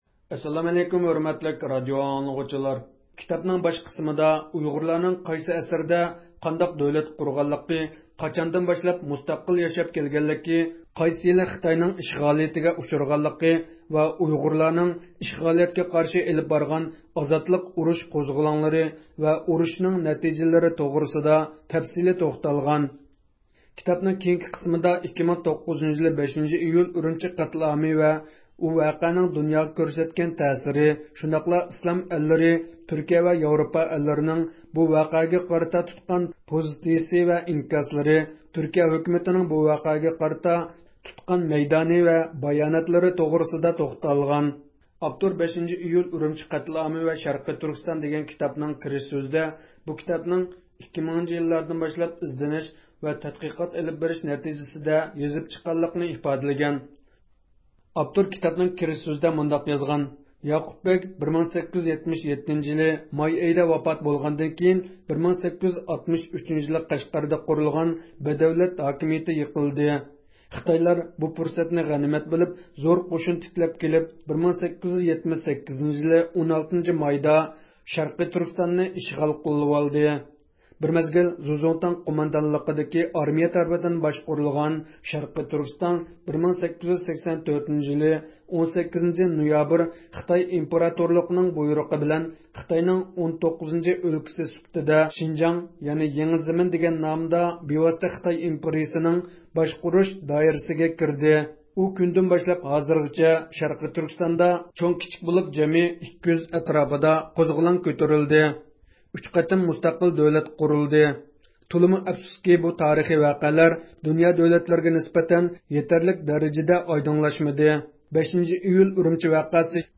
سۆھبەت ئېلىپ باردۇق.